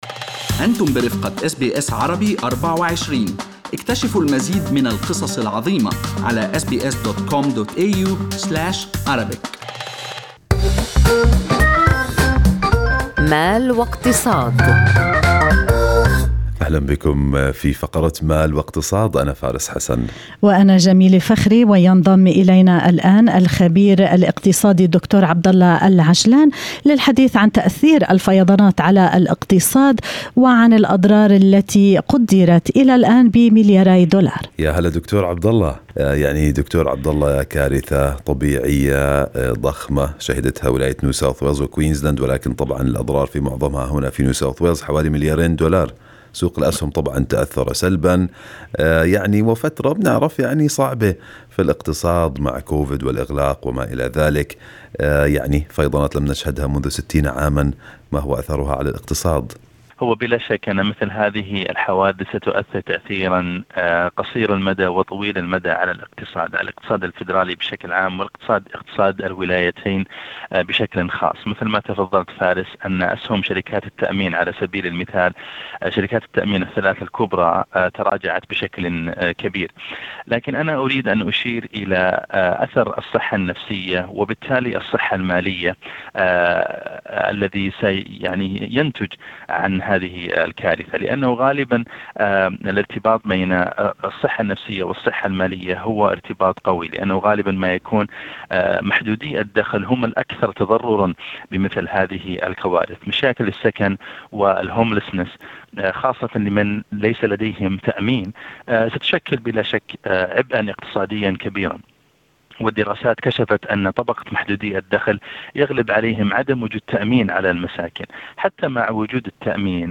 وفي حديث له مع اس بي اس عربي 24